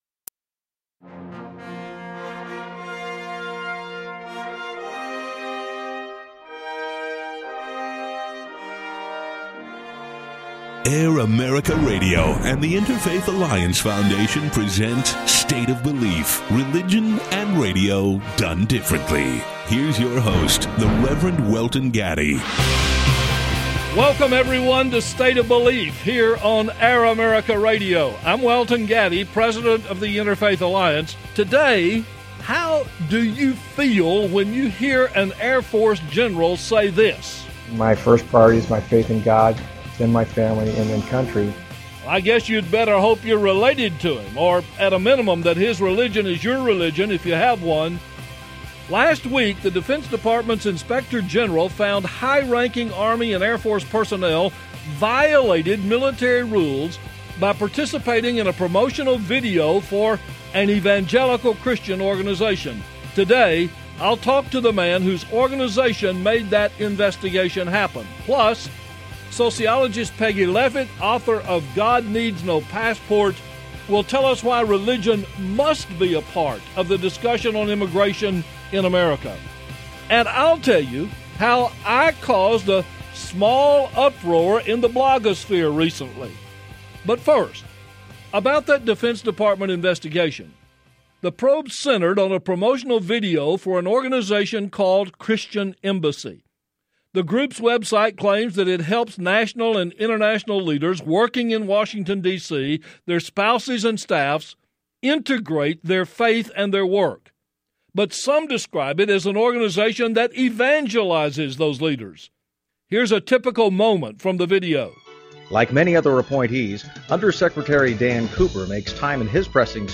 State of Belief Religion and radio, done differently Brought to you by The Interfaith Alliance Foundation Saturday morning 10am-11am ET and Sunday evening 7pm-8pm ET Air America Radio Network